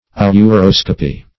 ouroscopy - definition of ouroscopy - synonyms, pronunciation, spelling from Free Dictionary Search Result for " ouroscopy" : The Collaborative International Dictionary of English v.0.48: Ouroscopy \Ou*ros"co*py\, n. [Gr.
ouroscopy.mp3